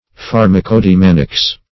Search Result for " pharmacodymanics" : The Collaborative International Dictionary of English v.0.48: Pharmacodymanics \Phar`ma*co*dy*man"ics\, n. [Gr. fa`rmakon medicine + E. dynamics.]
pharmacodymanics.mp3